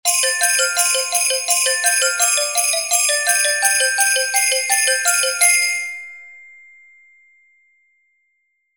Jingle_Bells_Presto_168.mp3